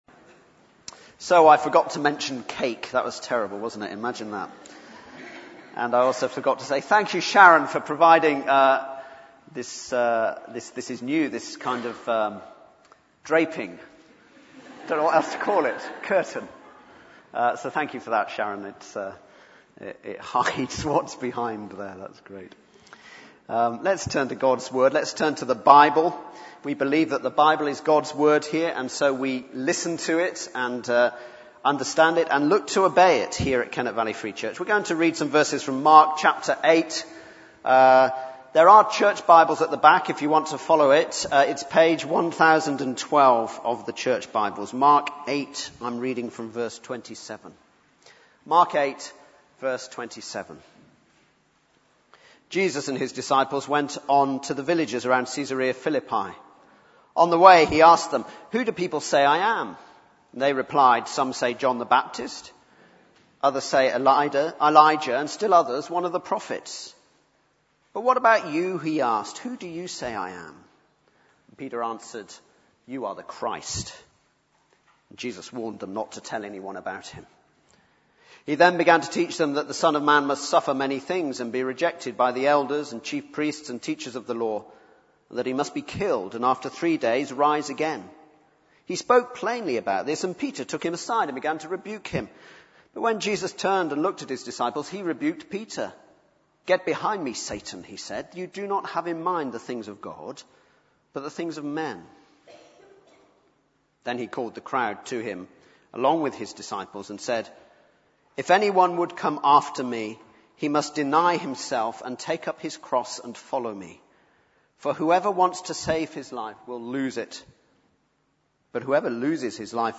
Baptismal Service – March 2012